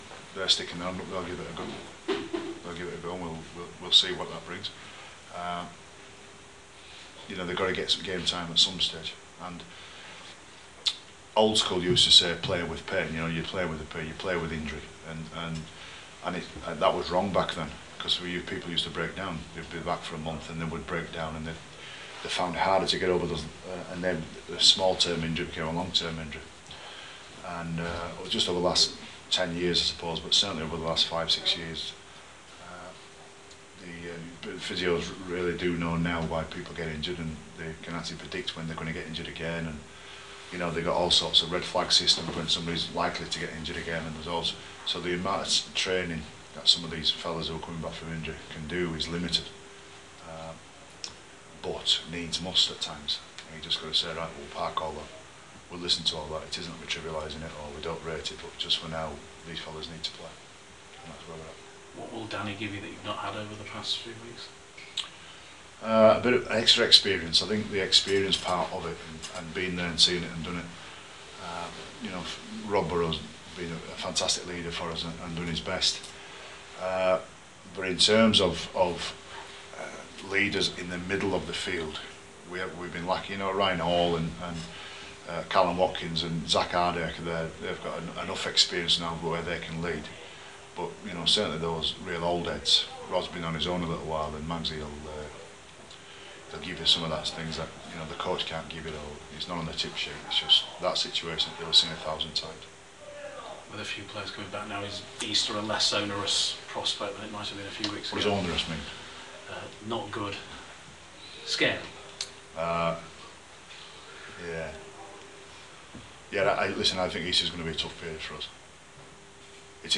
caught up with the Rhinos coach